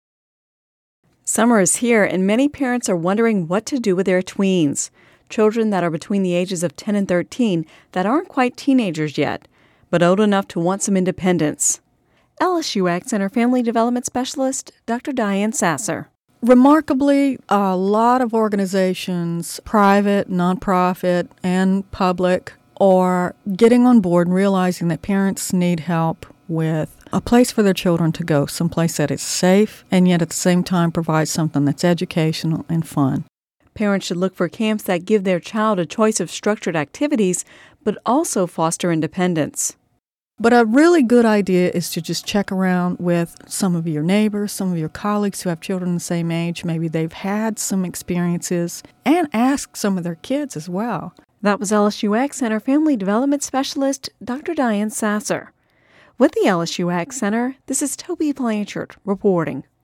(Radio News 05/31/10) With the end of school here, many parents are wondering what to do with their tweens – children between the ages of 10 and 13 that aren’t quite teenagers yet, but old enough to want some independence.